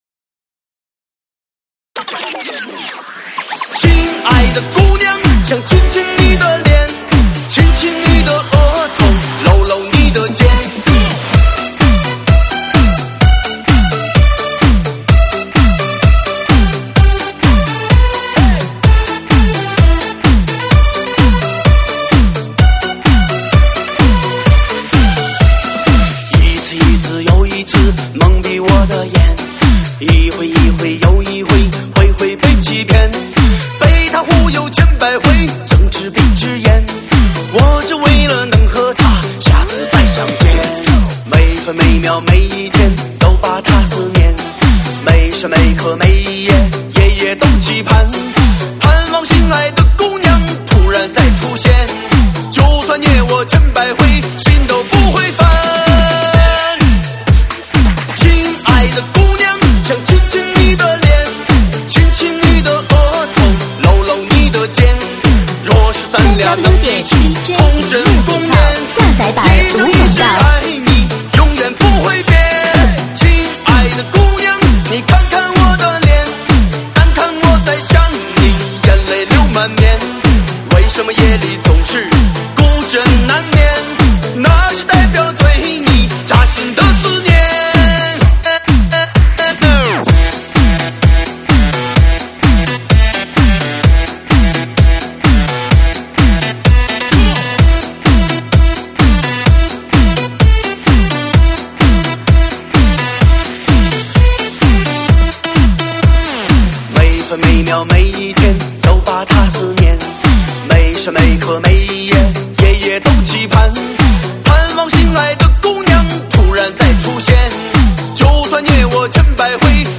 舞曲类别：快四